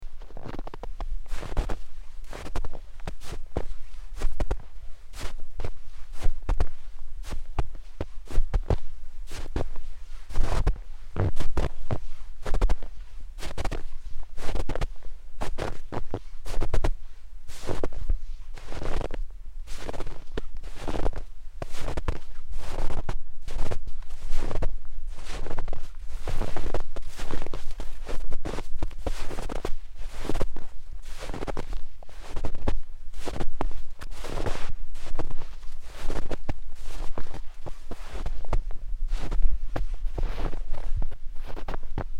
Walk in the snow
Footsteps in fresh snow
Recording of a slow walk in fresh snow fallen in January 2016 in Arosa, Switzerland.
Captured with iPhone 6 and Rode iXY.